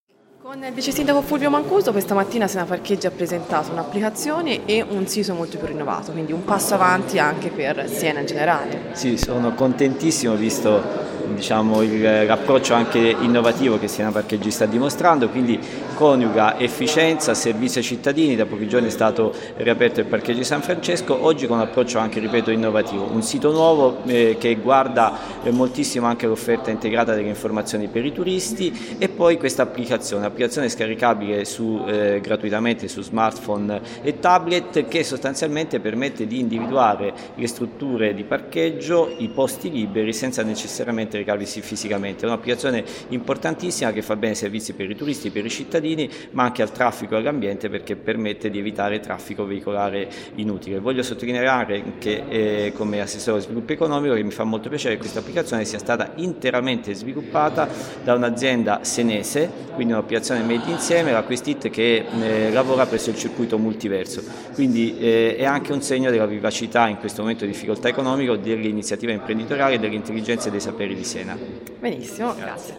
Fulvio Mancuso vice sindaco Siena